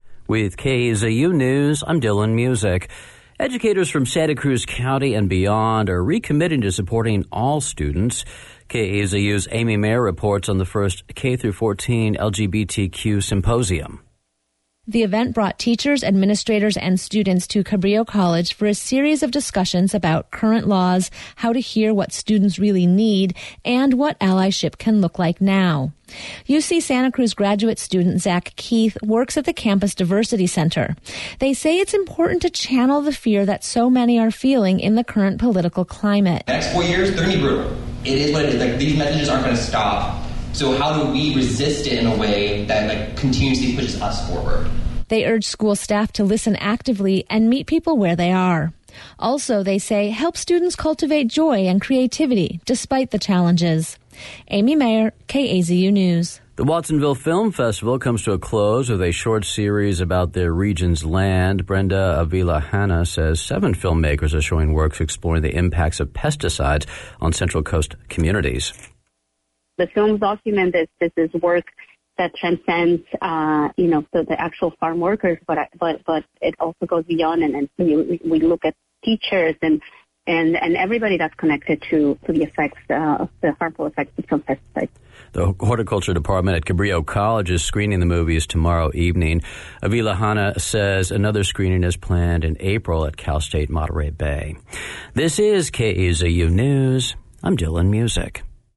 The Listen Local podcast delivers the most recent KAZU produced local news and information features directly to your device.